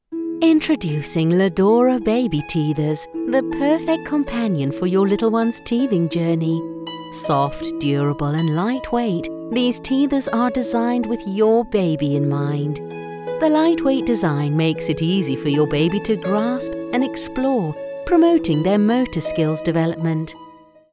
Female
Explainer Videos
Gentle & Nurturing Baby Product
0411Baby_Product_Gentle_nurturing.mp3